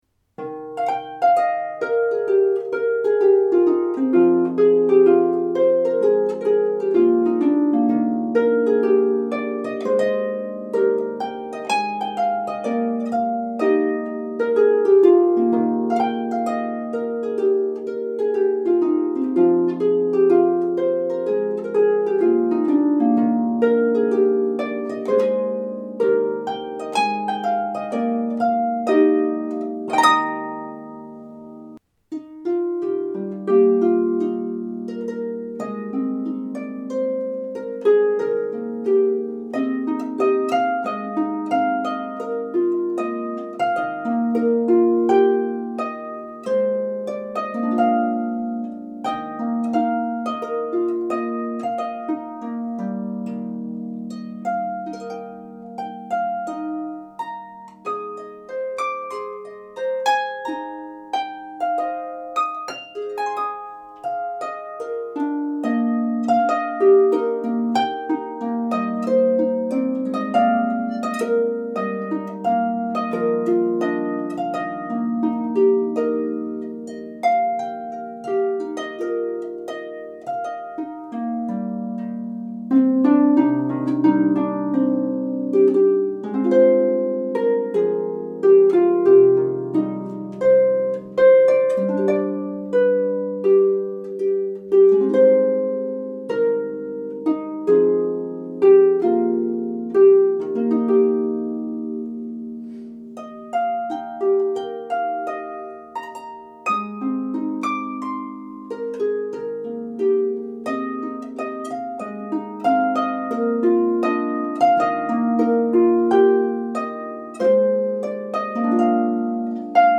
This piece is for lever harps with 26+ strings.